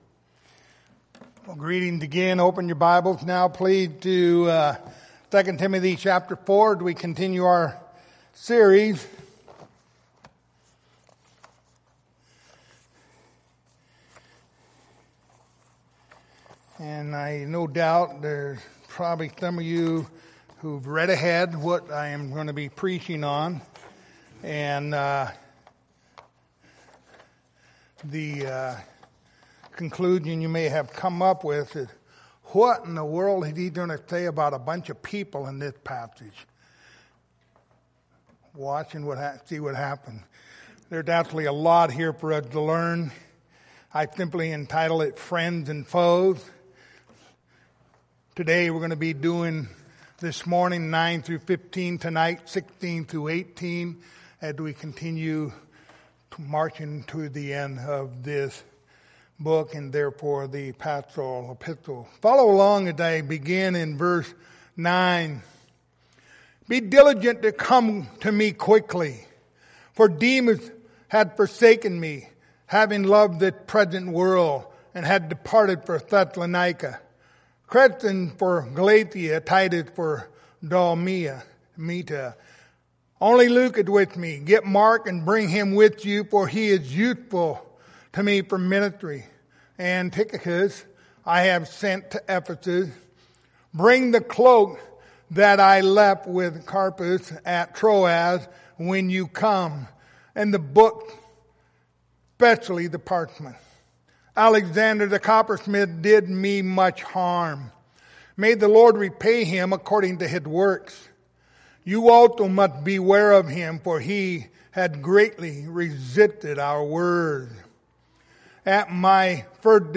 Pastoral Epistles Passage: 2 Timothy 4:9-15 Service Type: Sunday Morning Topics